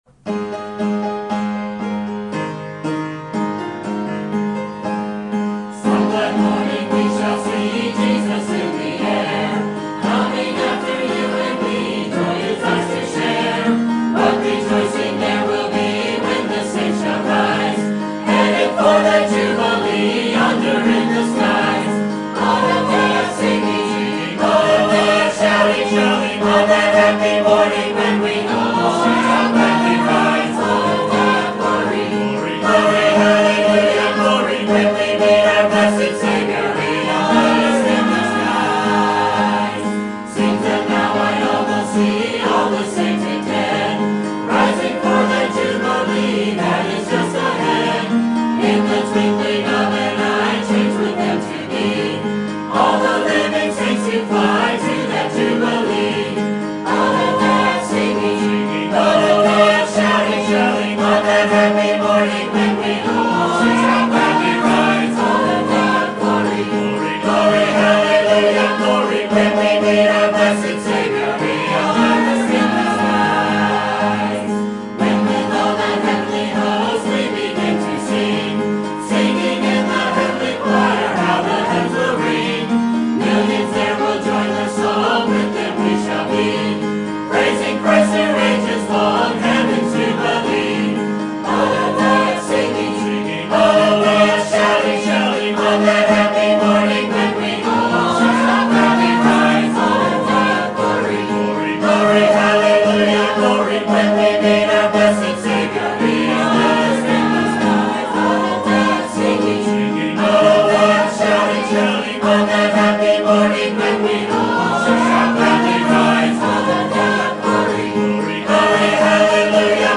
Sermon Topic: Baptist History Conference Sermon Type: Special Sermon Audio: Sermon download: Download (38.2 MB) Sermon Tags: Leviticus Baptist History Liberty